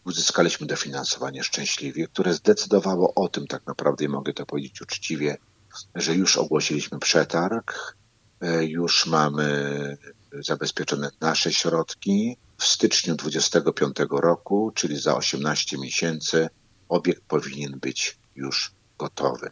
Cel został zrealizowany podsumowuje Dariusz Łukaszewski: